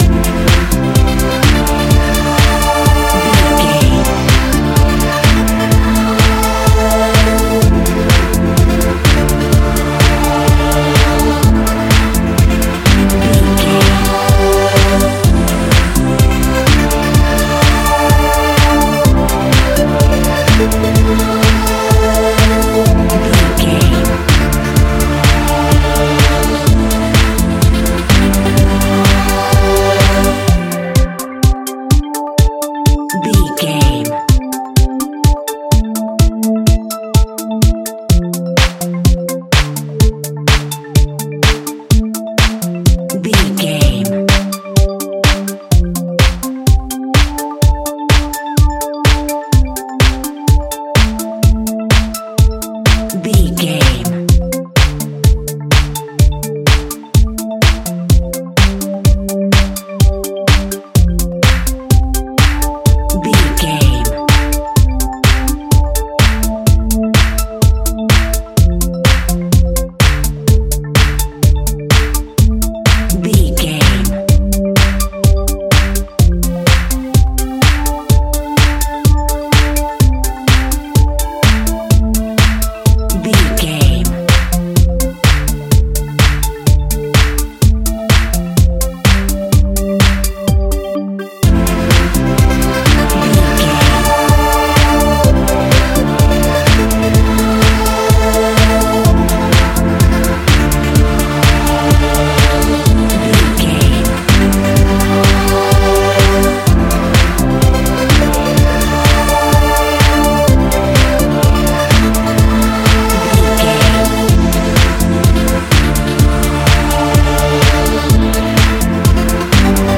Ionian/Major
Fast
uplifting
lively
futuristic
hypnotic
industrial
driving
drum machine
synthesiser
house
techno
electro
synth bass
Synth Strings
synth lead
synth drums